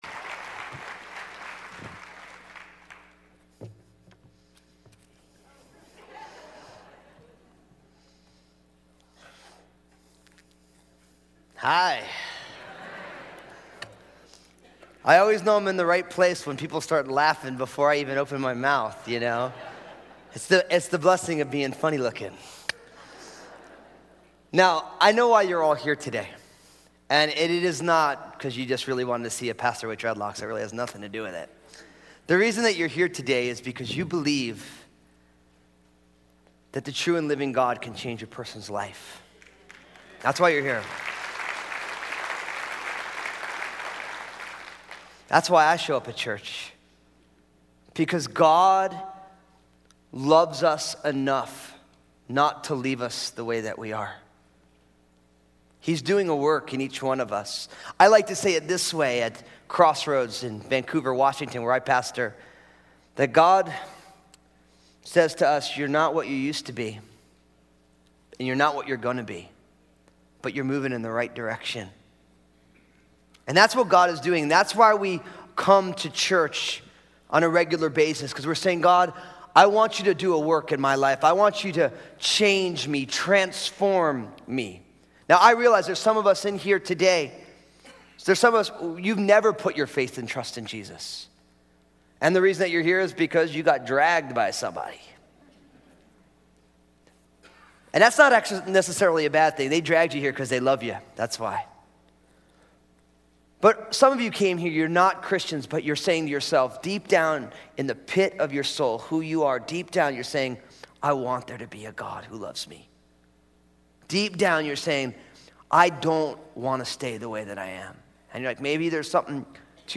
Guest speakers